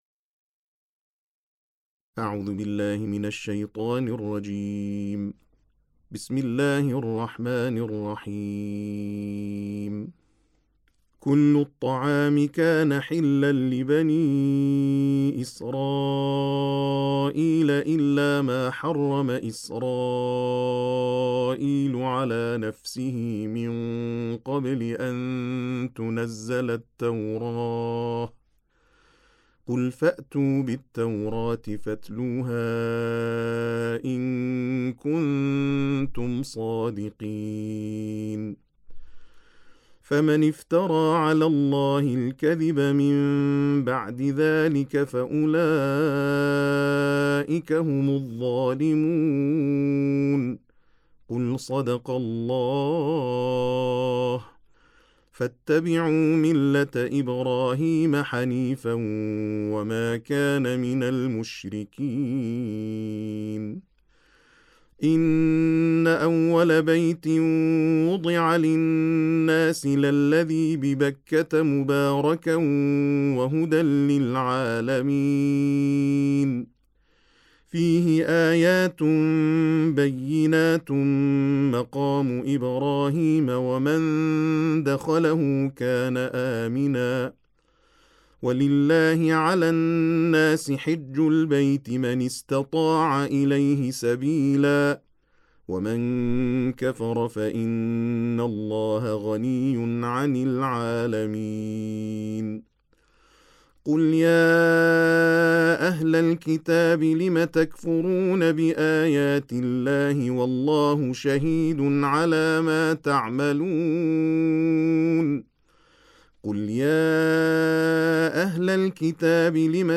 ترتیل جزء چهارم قرآن کریم